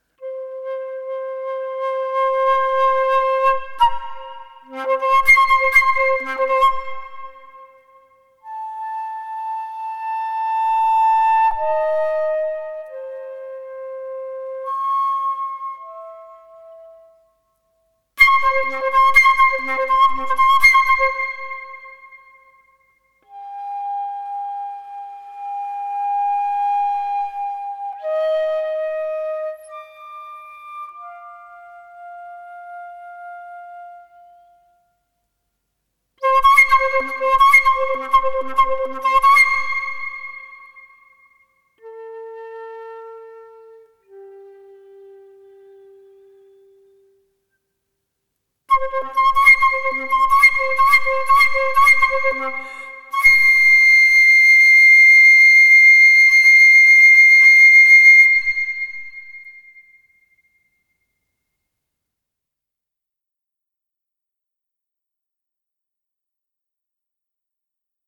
Камерно-инструментальная музыка